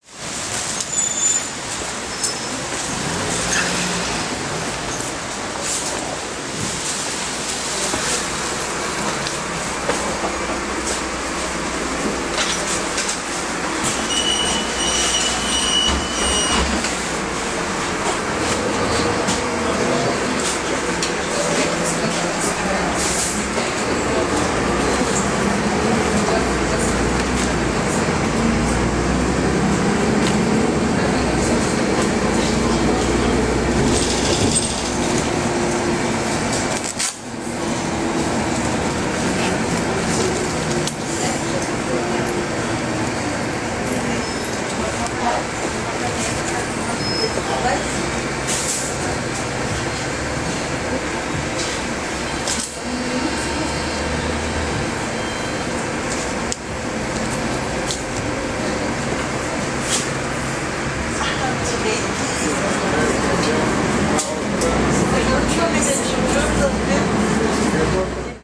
描述：此文件已用H4N立体声微音器录制
标签： WAV 气氛 晚上 街道 汽车 公交车 现场录音
声道立体声